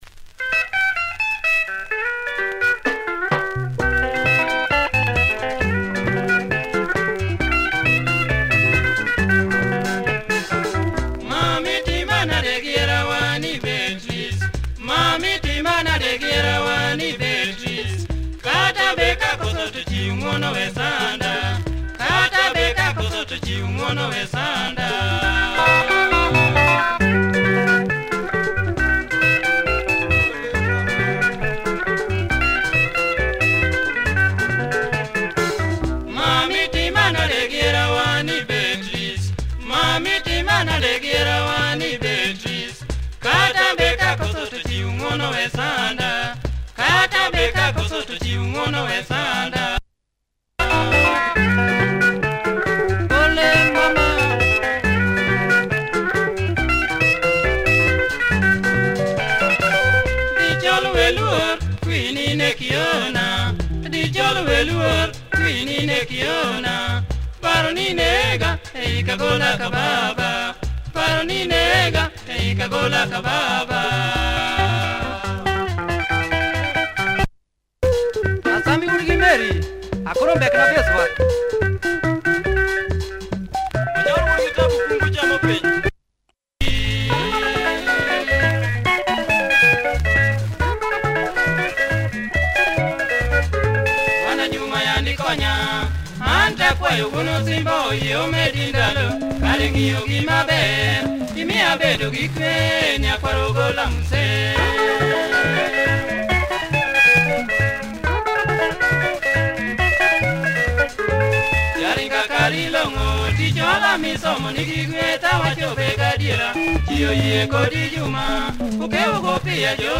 Tight LUO benga